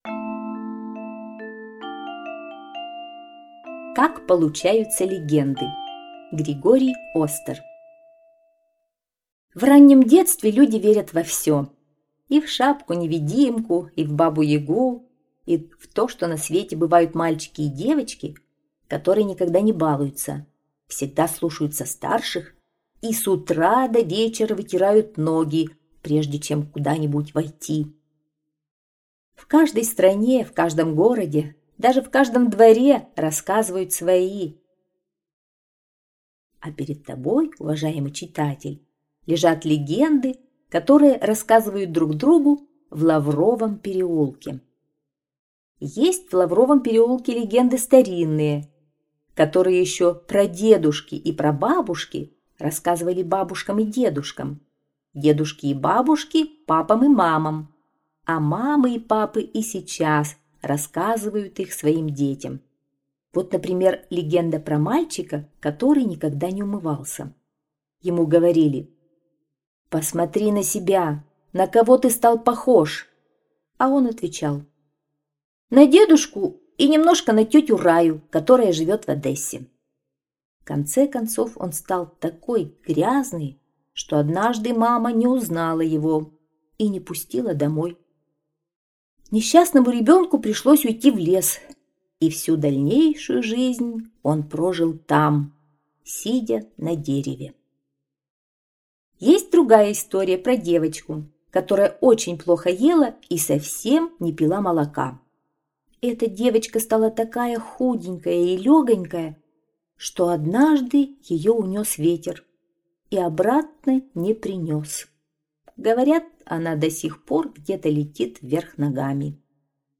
Как получаются легенды - аудио рассказ Остера Г.Б. Рассказ о том, как получаются легенды, ведь в раннем детстве люди верят во всё.